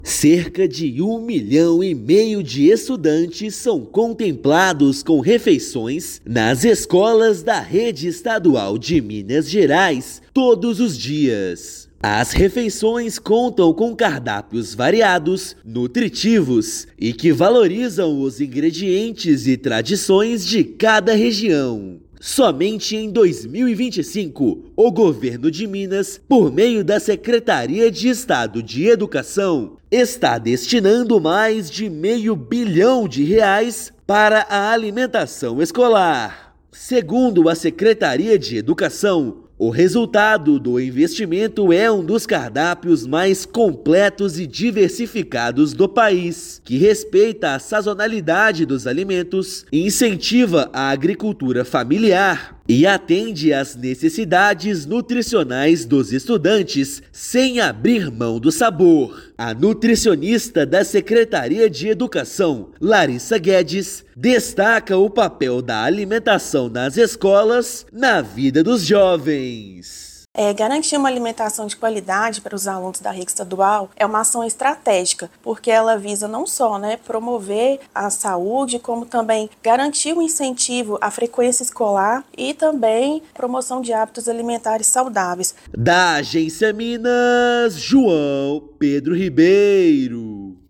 Só em 2025, Estado está investindo mais de R$ 500 milhões para oferecer alimentação saudável e adaptada à cultura de cada região. Ouça matéria de rádio.